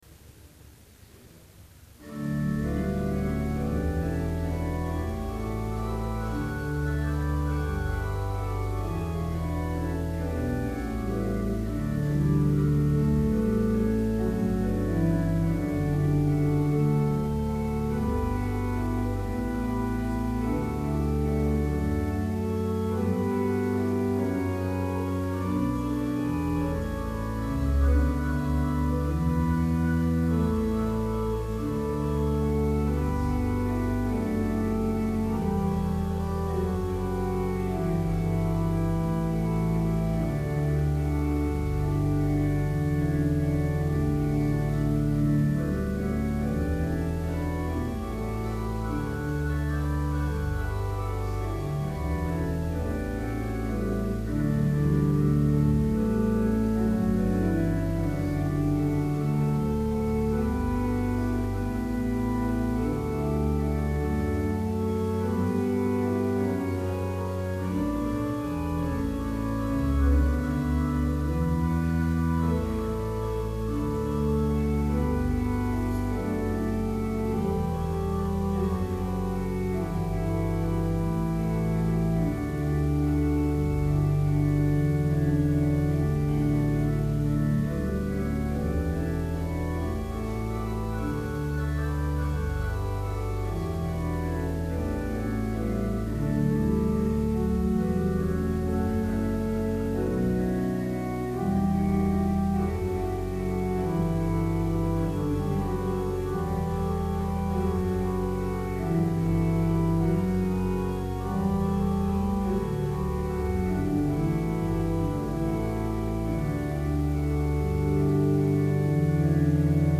Complete service audio for Summer Chapel - May 30, 2012